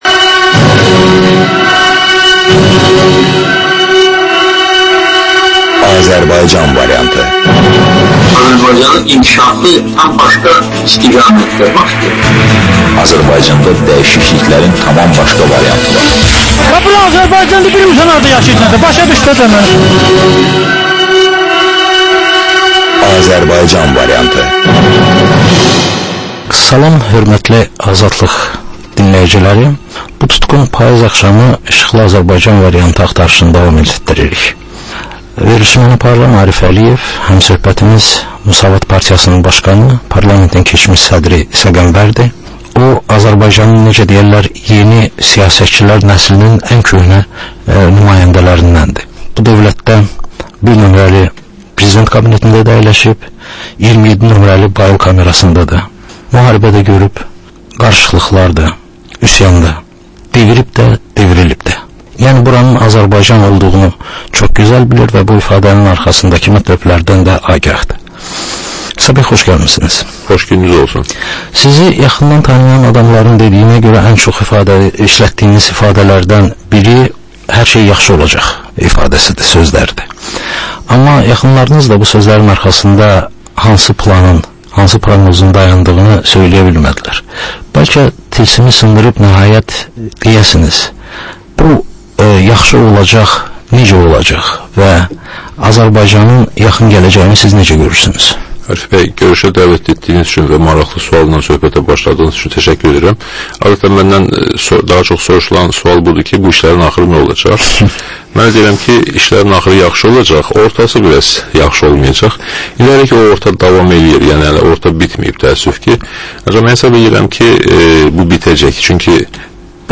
Dəyirmi masa söhbətinin təkrarı.